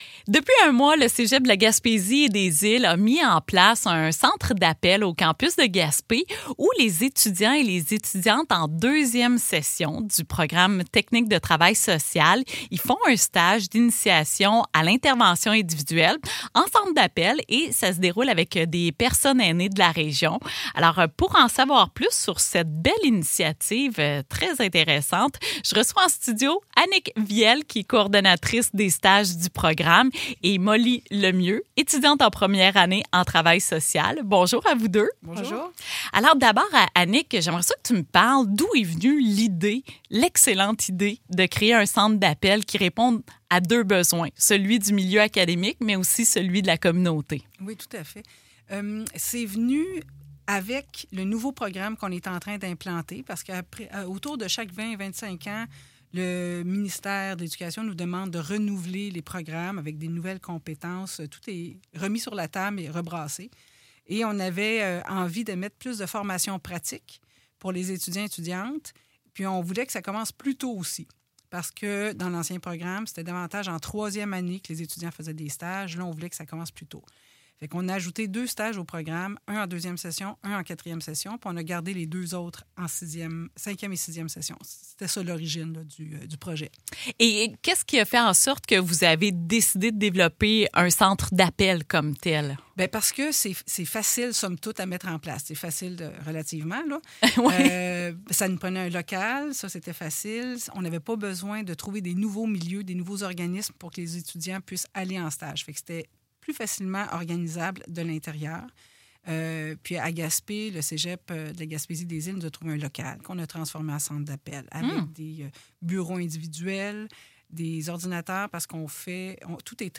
Pour en savoir plus sur cette initiative, je reçois en studio